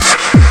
NOISREVER1-L.wav